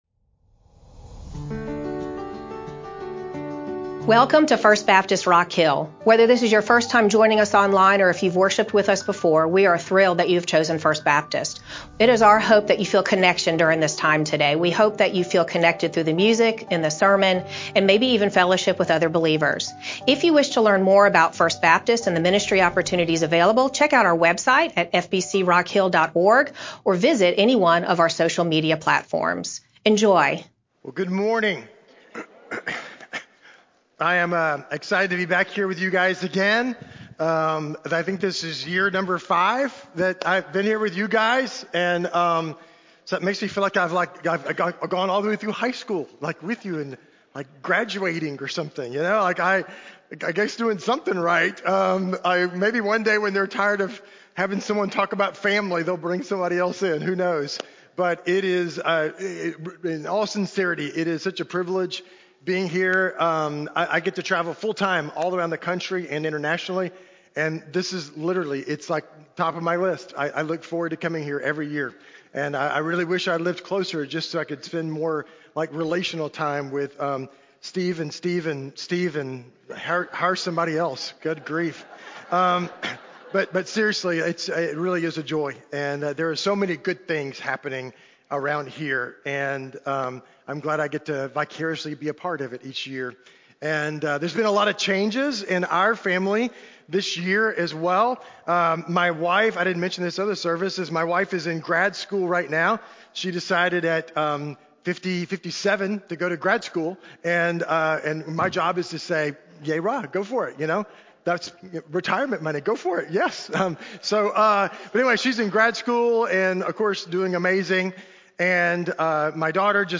Feb-22-Sermon-CD.mp3